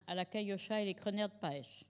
Enquête Arexcpo en Vendée
Collectif-Patois (atlas linguistique n°52)
Catégorie Locution